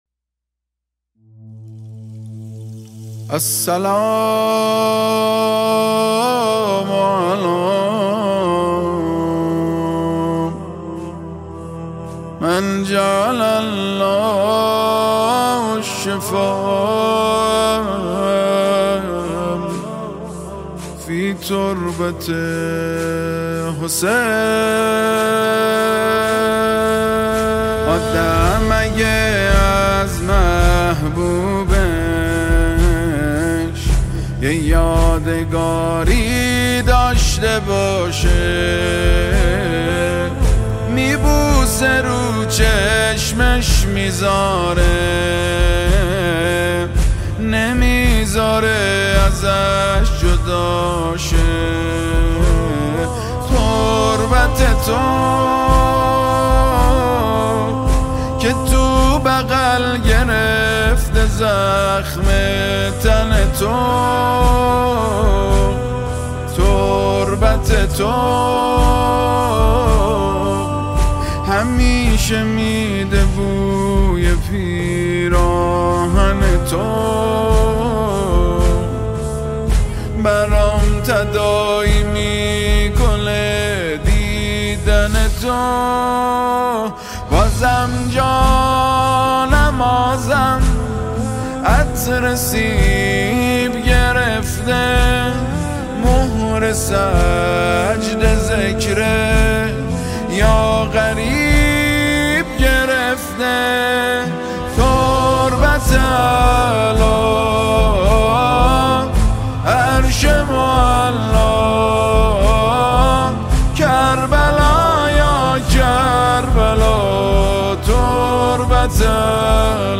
مداحی استودیویی